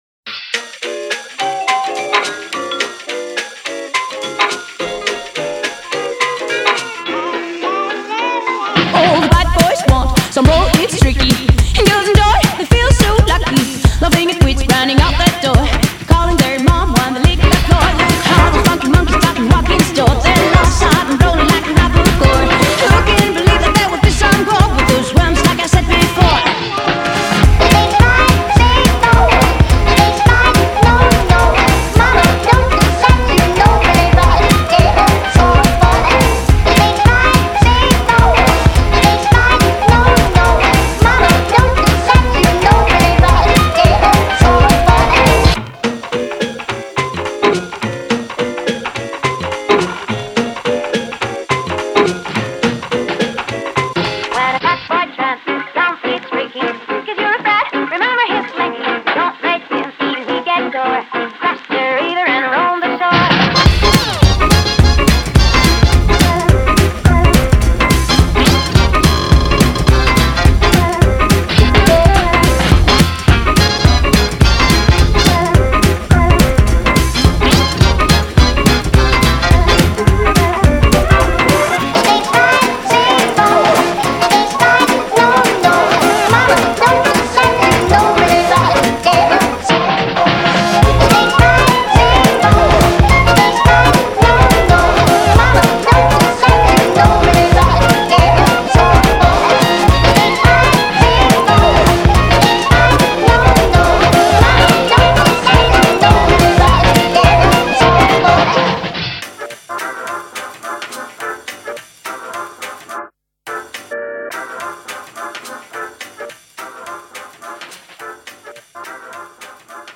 BPM212
Audio QualityPerfect (High Quality)
Comments[ELECTRO SWING]